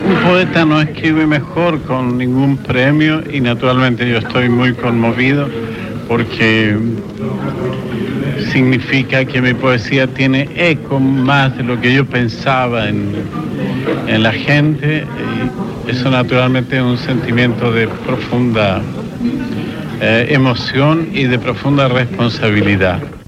Paraules de Pablo Neruda el dia en que va rebre el Premi Nobel de Literatura.
Extret de Crònica Sentimental de Ràdio Barcelona emesa el dia 29 d'octubre de 1994.